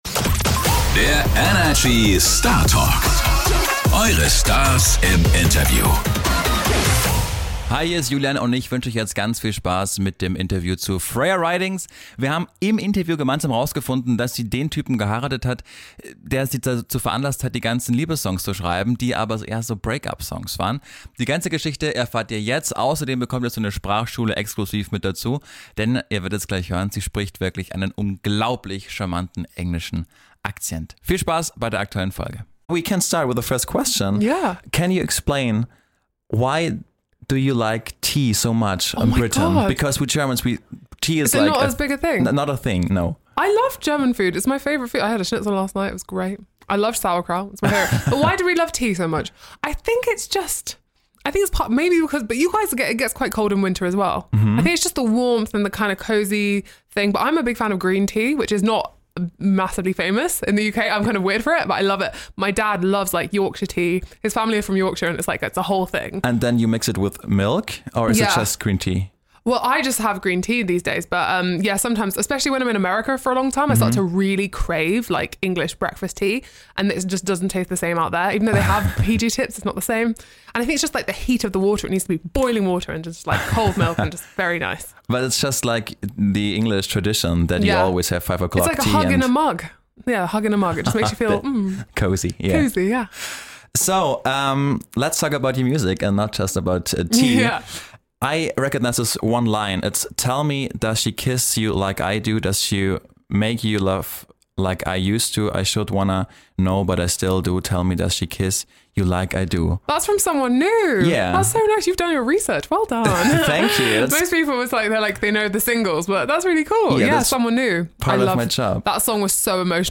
Beschreibung vor 2 Jahren Freunde, diese Woche war Freya Ridings bei uns in den ENERGY-Studios.